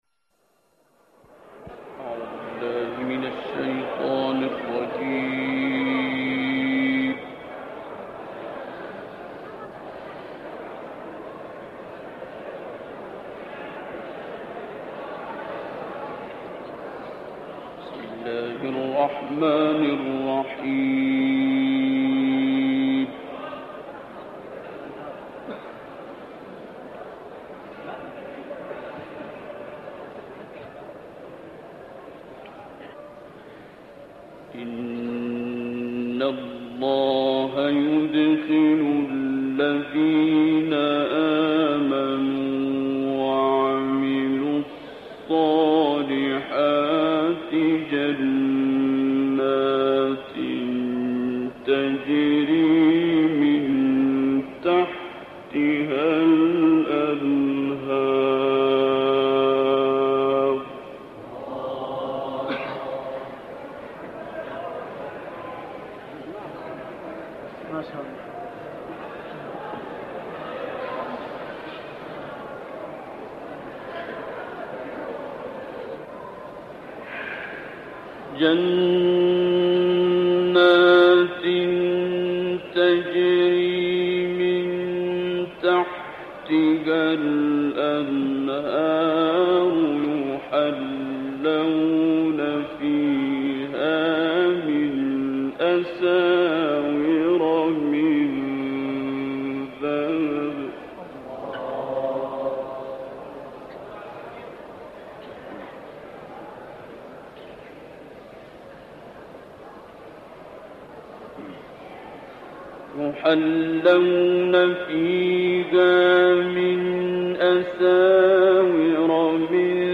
تلاوت آیات ۴۱-۳۵ سوره ابراهیم با صدای استاد عبدالباسط + دانلود
گروه فعالیت‌های قرآنی: قطعه‌ای زیبا از تلاوت عارف‌القرا، استاد عبدالباسط از آیات ۴۱-۳۵ سوره ابراهیم، آیات ۲۶-۸ سوره غاشیه و سُوَر شمس، ضحی، شرح، تین، حمد و آیات ابتدایی سوره بقره ارائه می‌شود.